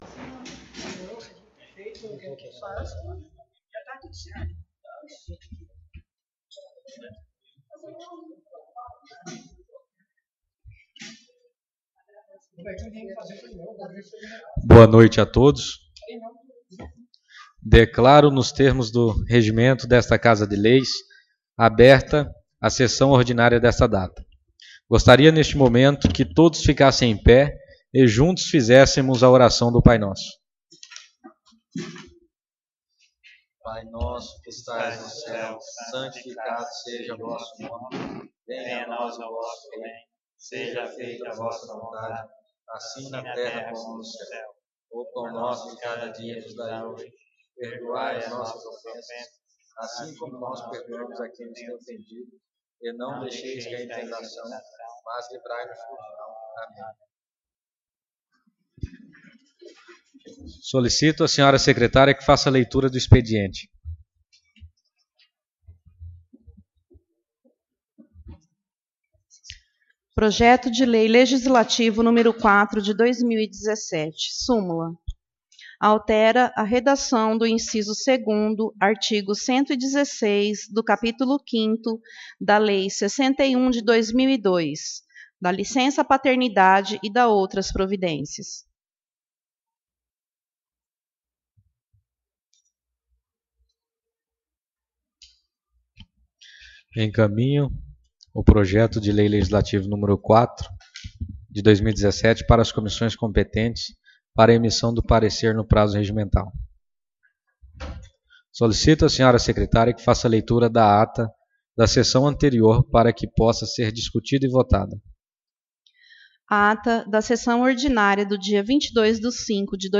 Sessão Ordinária 05/06/2017